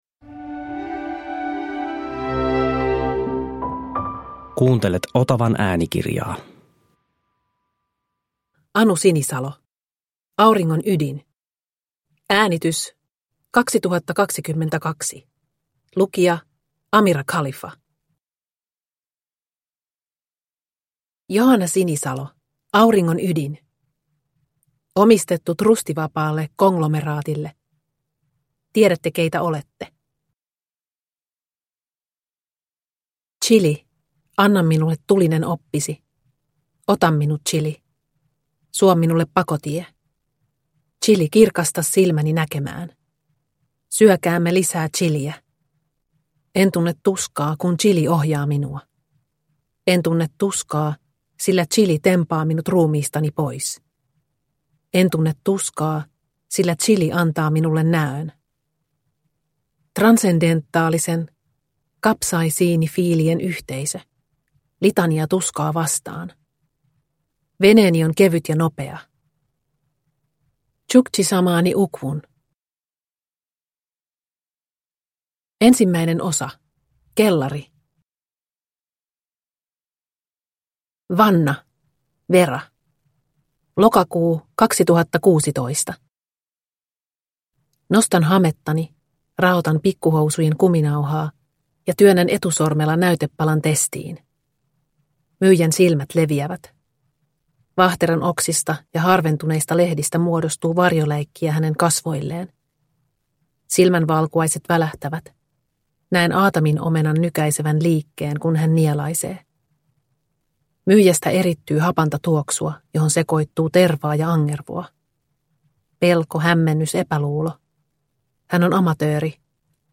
Auringon ydin – Ljudbok – Laddas ner